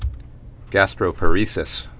(găstrō-pə-rēsĭs, -părĭ-sĭs)